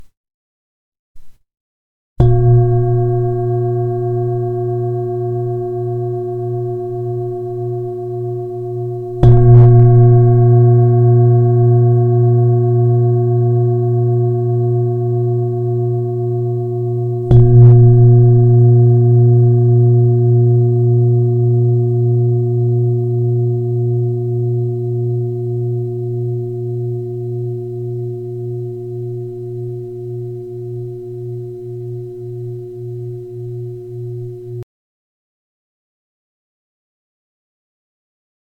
Zdobená tibetská mísa A#B 29cm
Nahrávka mísy úderovou paličkou:
Jde o ručně tepanou tibetskou zpívající mísu dovezenou z Nepálu.